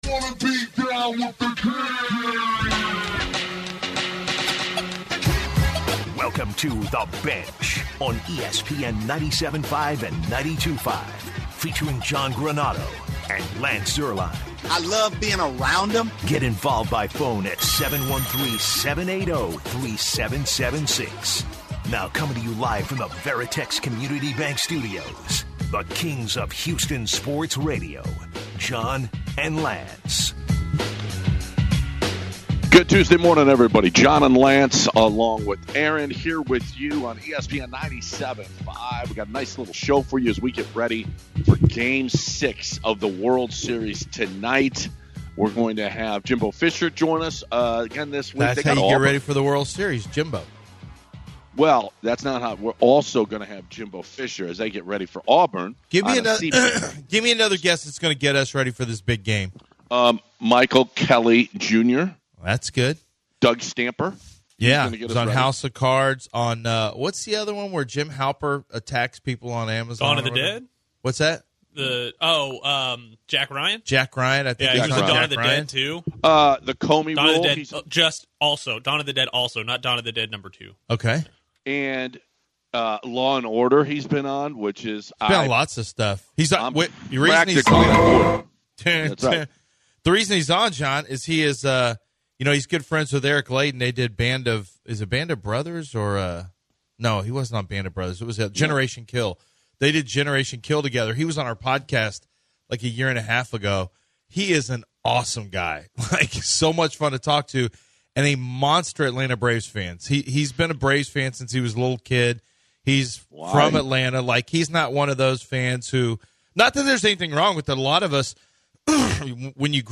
Head Football Coach of Texas A&M, Jimbo Fisher joins The Bench to discuss their next game against Auburn breaking down the game plan before moving on to news around the NFL breaking down the power rankings as well as the trade deadline coming up and what that impacts. Actor and Braves Fan, Michael Kelly Jr. joins The Bench talking about the World Series and game 6 tonight between the Astros-Braves.